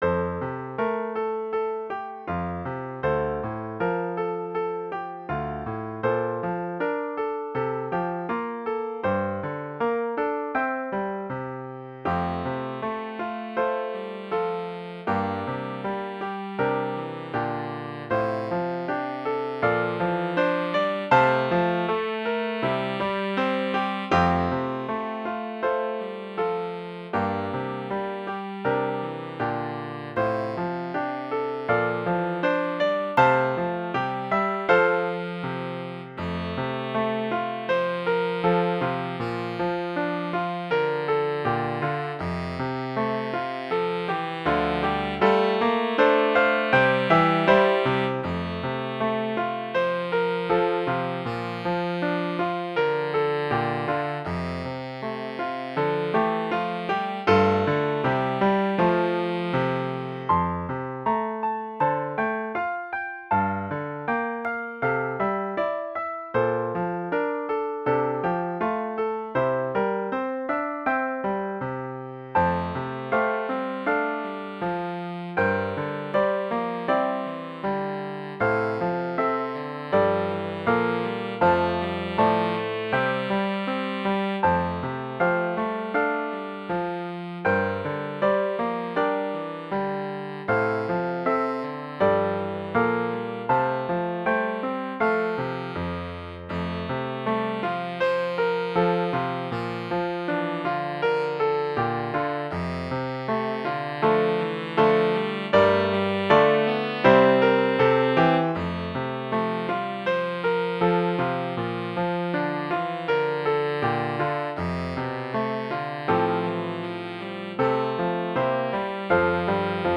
Intermediate Instrumental Solo with Piano Accompaniment.
Christian, Gospel, Sacred.
gentle, meditative mood.